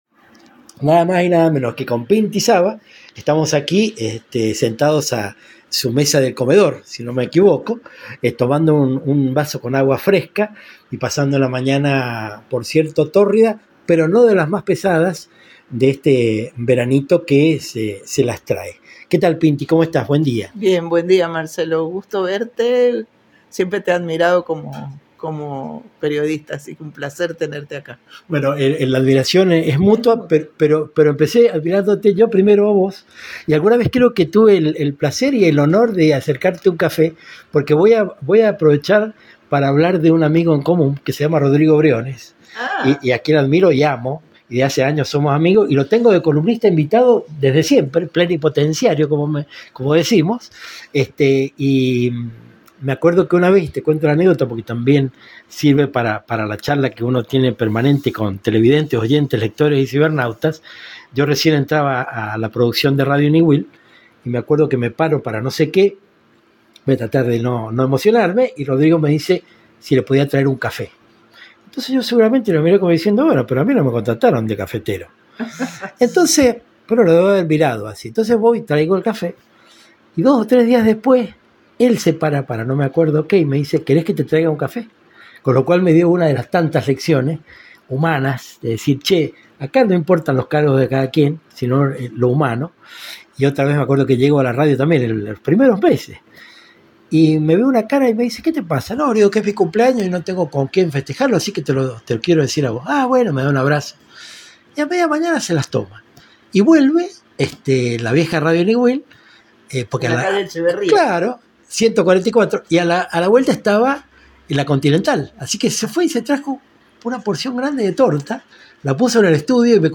Realizar una entrevista siempre rezume aventura.